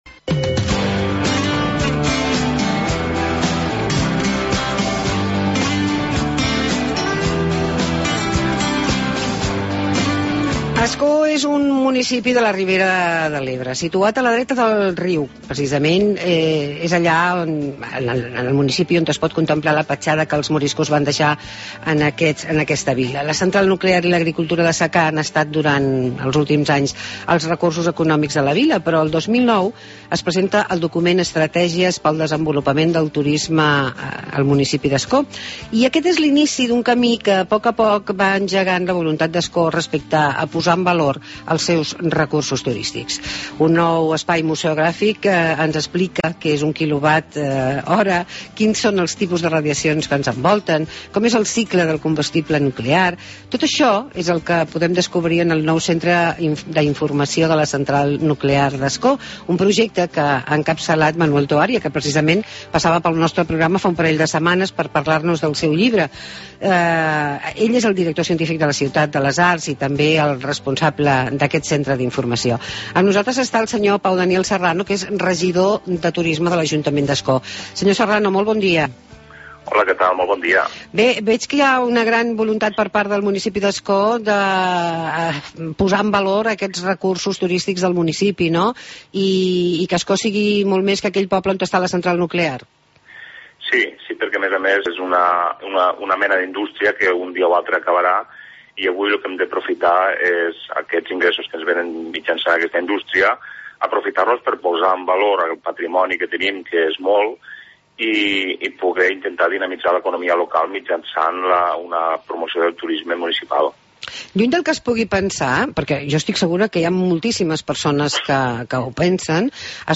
Proposta ACT: Pau Daniel Serrano, regidor Turisme Ascó, ens parla dels recursos turístics de la localitat